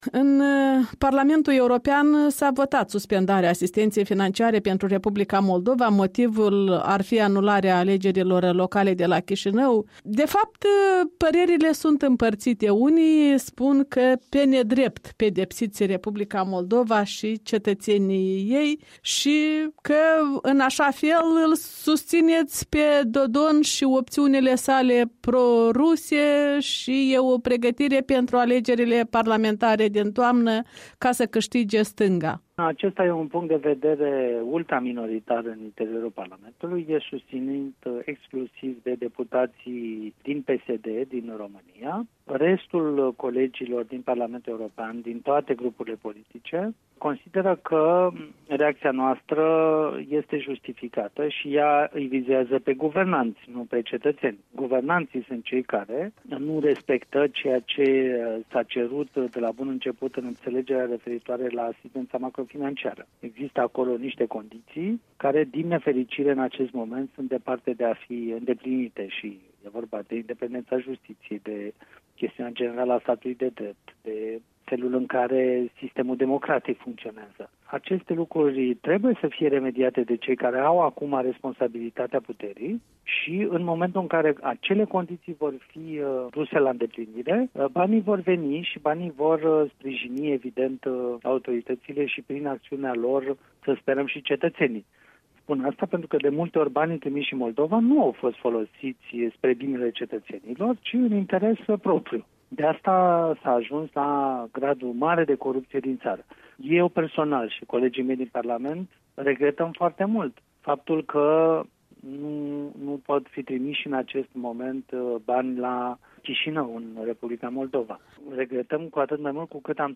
Interviu cu eurodeputatul român Cristian Preda